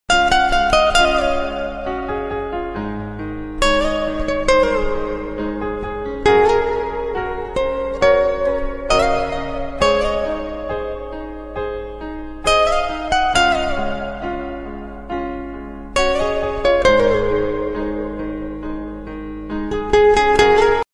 No wind, just good vibes!